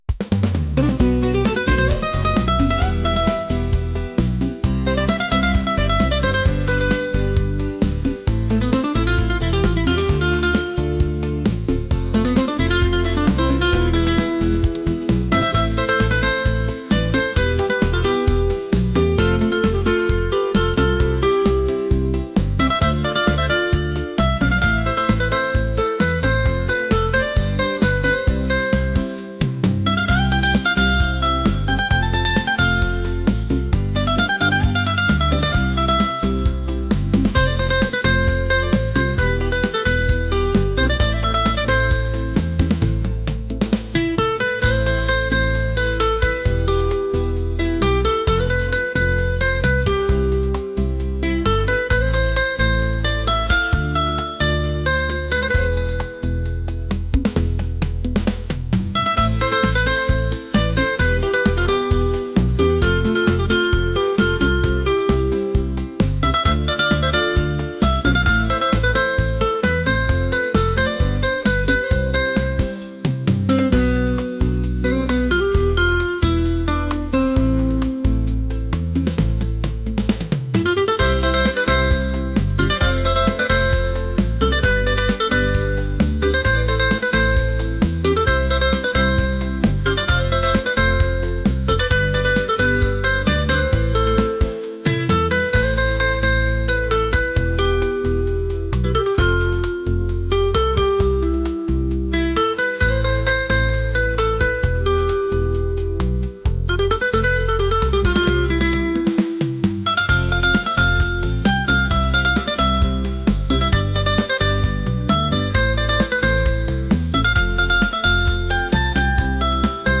energetic | romantic | latin | pop